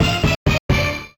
jingles-hit_14.ogg